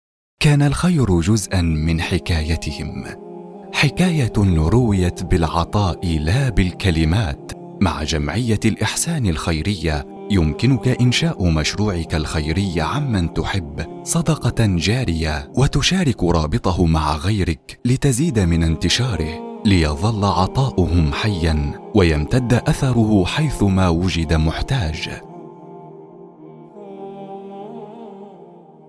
تعليق صوتي دافئ وهادئ لإعلان خيري صوت مؤثر وواثق
في هذه العينة، أقدّم تعليقًا صوتيًا دافئًا وهادئًا لإعلان خيري يحمل طابعًا إنسانيًا مؤثرًا اعتمدتُ أسلوبًا رصينًا يمزج بين الوضوح والهدوء والقرب العاطفي، بهدف إيصال رسالة الجمعية بروح مطمئنة تُلامس المستمع وتدفعه للتفاعل تم تسجيل الصوت بجودة عالية، مع المحافظة على نبرة ثابتة، ووثوقية تشبه الرسائل الرسمية الهادفة هذا الأداء مناسب للإعلانات الخيرية، الحملات الإنسانية، المبادرات المجتمعية، والمشروعات التي تتطلب صوتًا قادرًا على بناء الثقة وتحريك المشاعر دون مبالغة إذا كنتم تبحثون عن صوت راقٍ يقدّم رسالة إنسانية بصدق وتأثير فهذه العينة توضح أسلوبي في هذا النوع من الأعمال